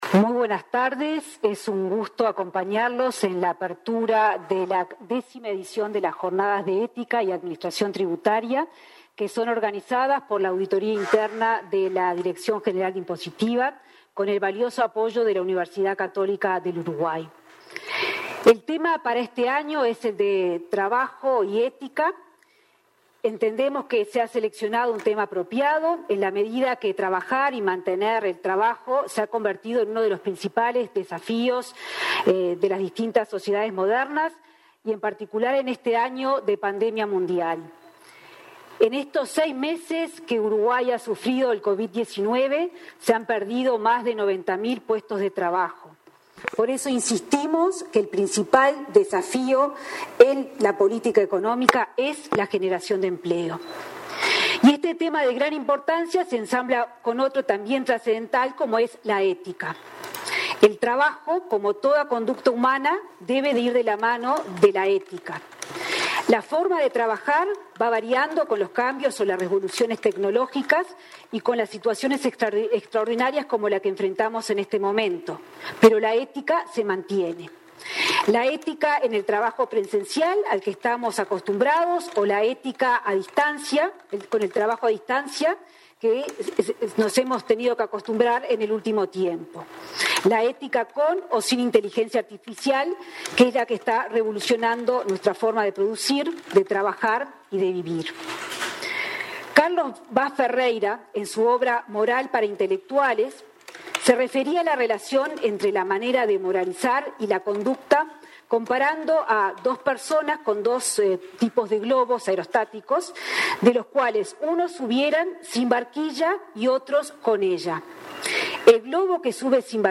En la apertura de las Jornadas sobre Ética y Administración Tributaria, la ministra de Economía, Azucena Arbeleche, afirmó que “este Gobierno le ha dado un rol fundamental a las auditorías, de manera de lograr que los recursos de todos los uruguayos se administren con eficacia, eficiencia y transparencia”.